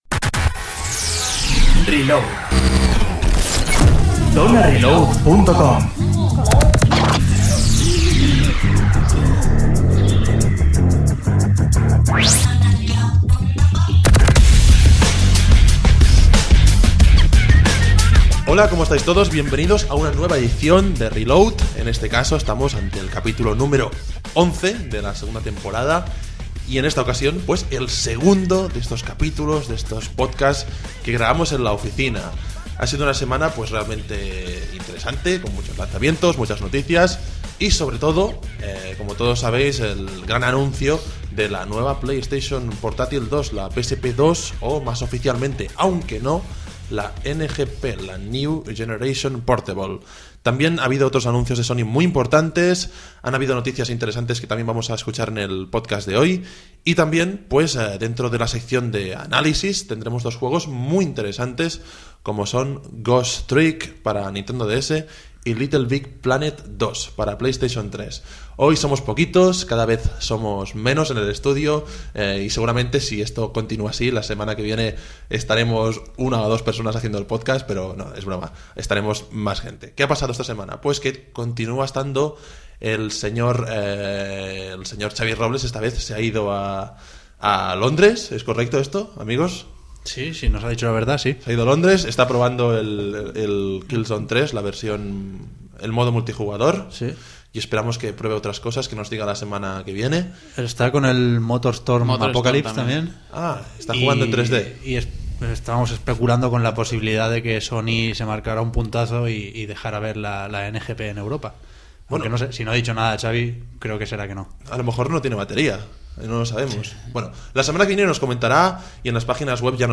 Descargar MP3 / Reproducir ahora Definitivamente, nos gusta esto de grabar el podcast en la oficina.